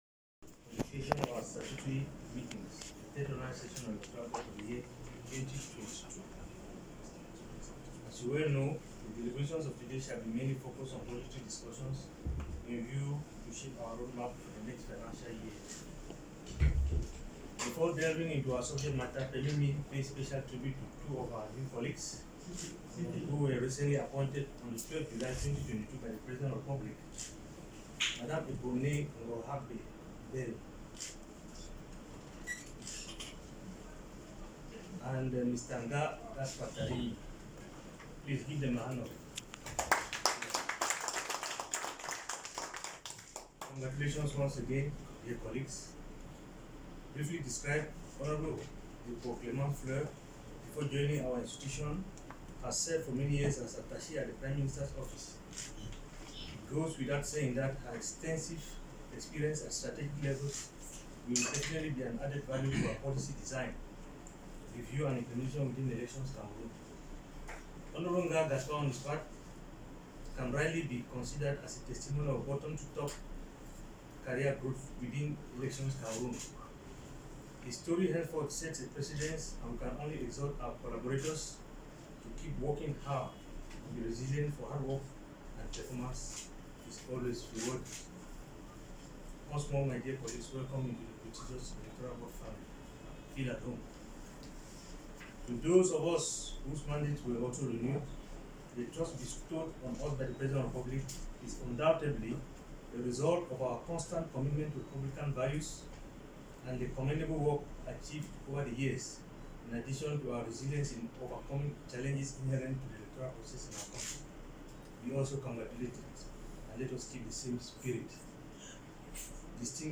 EXTRAIS DU DISCOURS DU PRÉSIDENT DU CONSEIL ÉLECTORAL